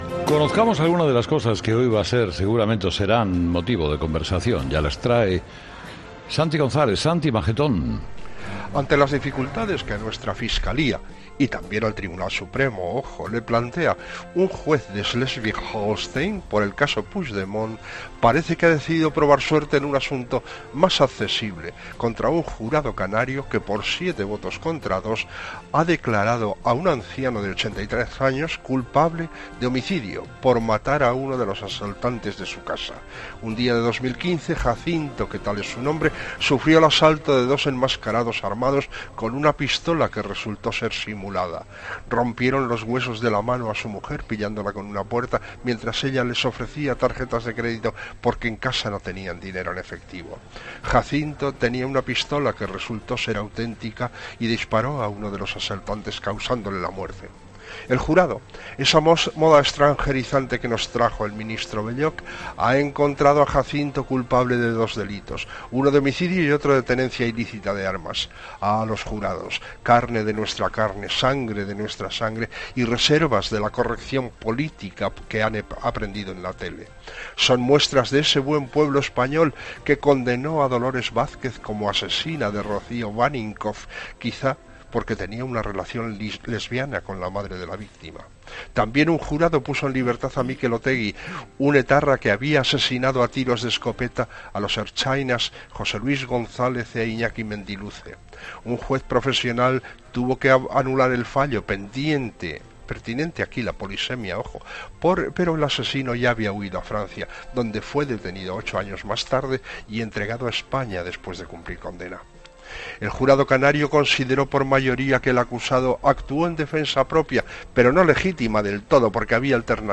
El comentario de Santiago González